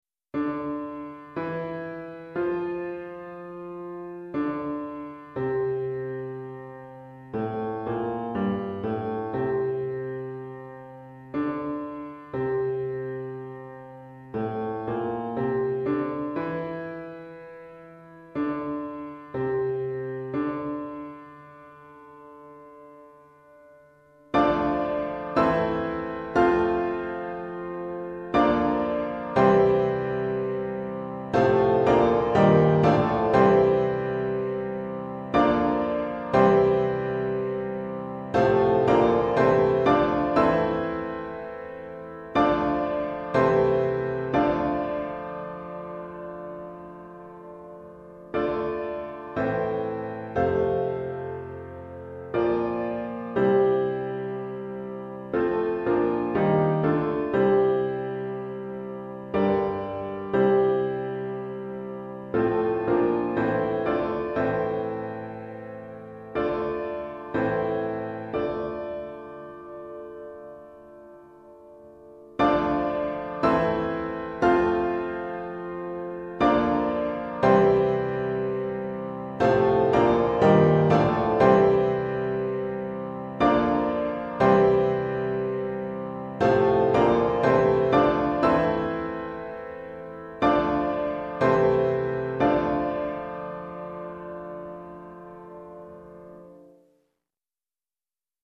Répertoire pour Piano